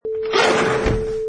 Sliding door closes
Product Info: 48k 24bit Stereo
Category: Doors / Sliding Doors
Relevant for: sliding, door, porch, open, opens, building, buildings, close, metal, loud, bang, locker, room, small.
Try preview above (pink tone added for copyright).
Sliding_Door_Closes_3.mp3